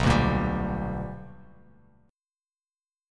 BmM7#5 chord